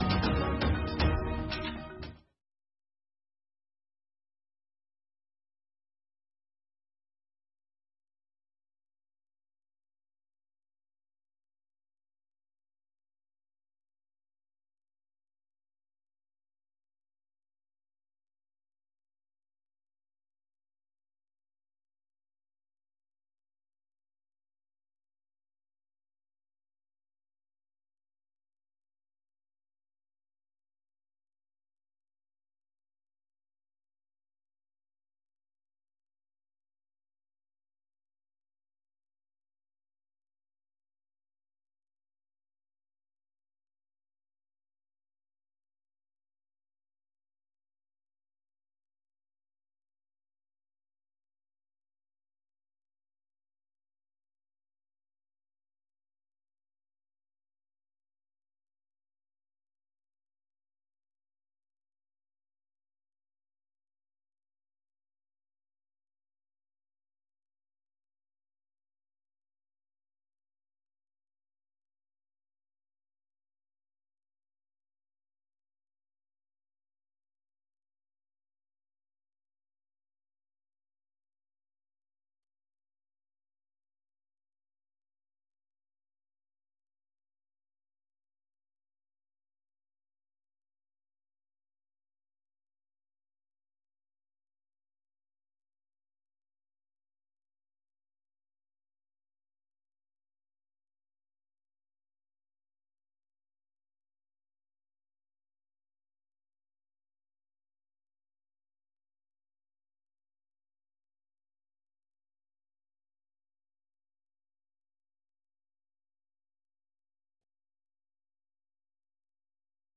Um programa orientado aos países Lusófonos de África, Brasil e comunidades de língua portuguesa no mundo. Oferece noticias do dia, informação, analises, desporto, artes, entretenimento, saúde, questões em debate em África. Às sextas em especial um convidado explora vários ângulos de um tema.